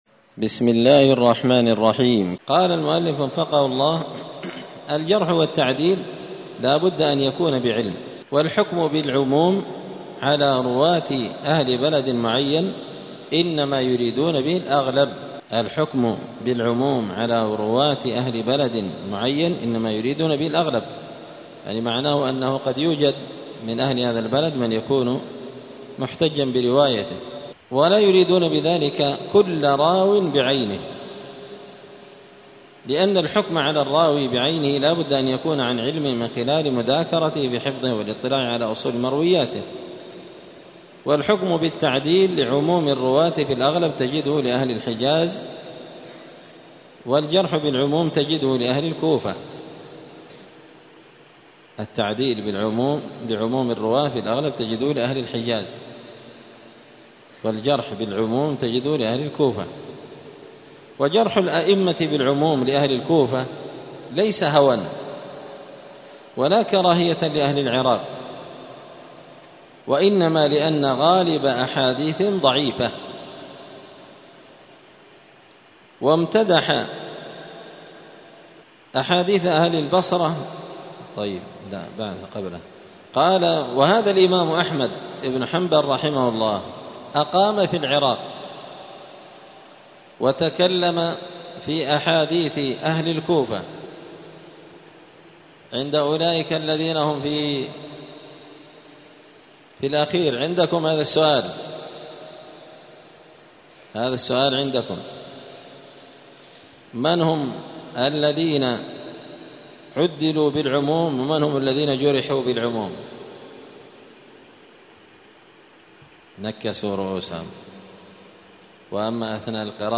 *الدرس السابع والخمسون (57) باب التعديل والتجريح بالعموم.*